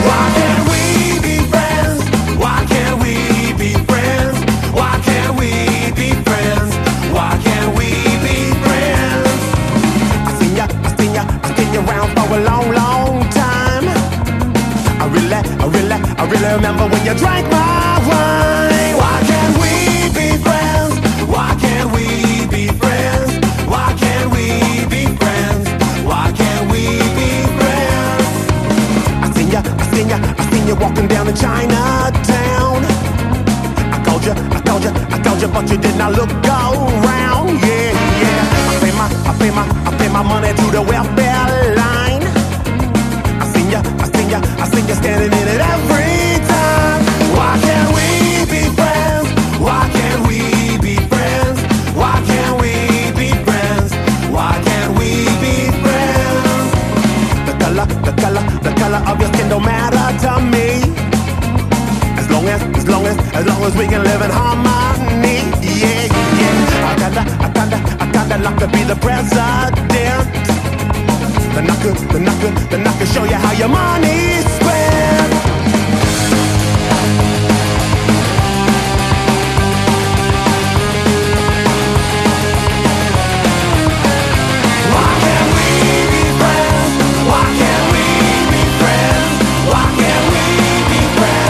カヴァー！